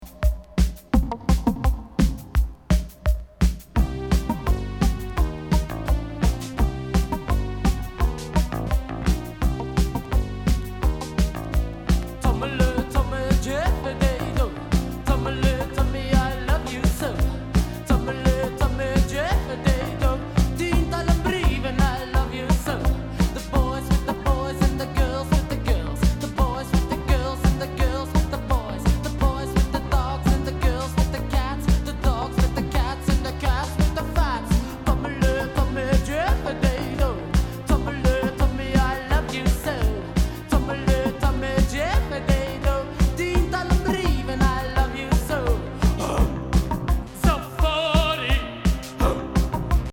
ファーストに収録の奇天烈シンセ・ビート